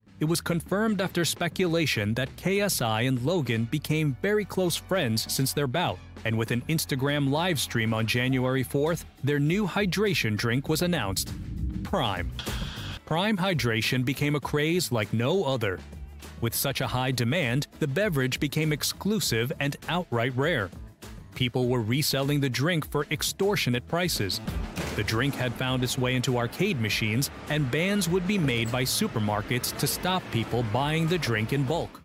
Male
Documentary
Full-Length Narration